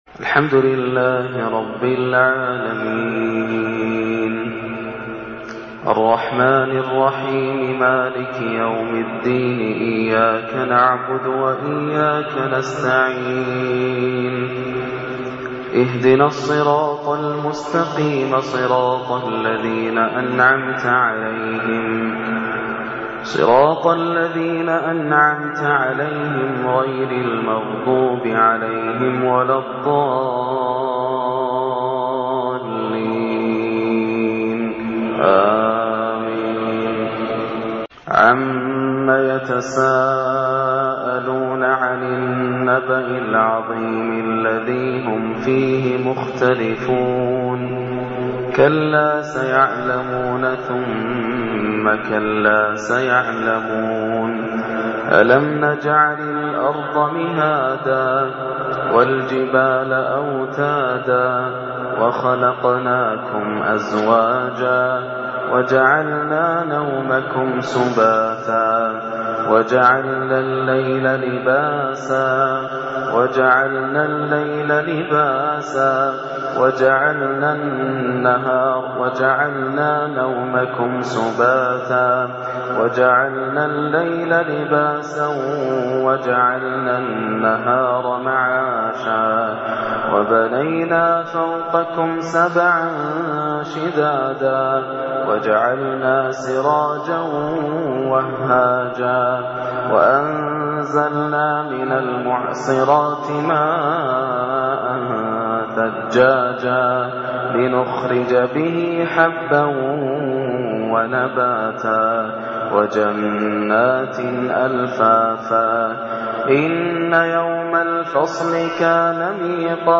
تلاوة لسورة النبأ | فجر 24 رمضان 1430 > عام 1430 > الفروض - تلاوات ياسر الدوسري